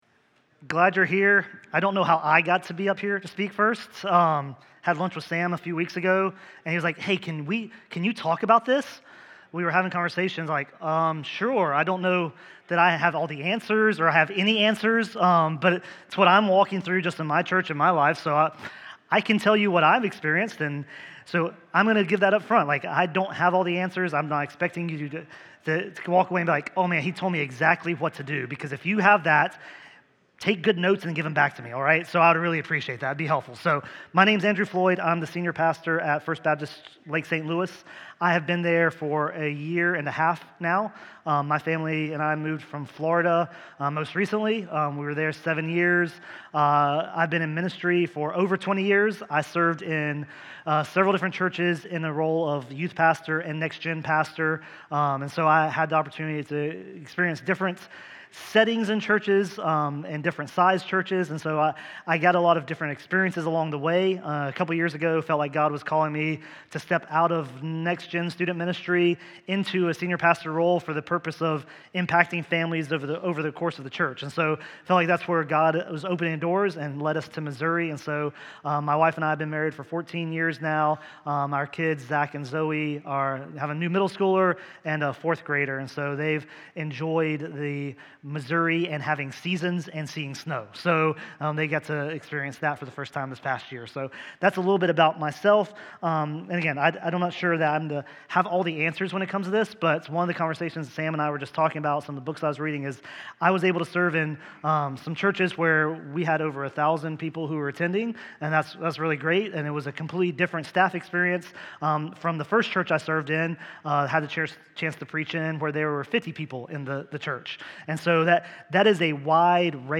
Sermons | Twin Rivers Baptist Association